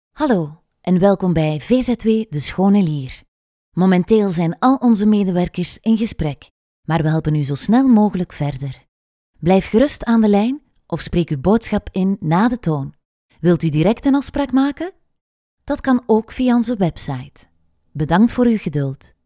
Flemish
Natural, Warm, Soft, Accessible, Friendly
Telephony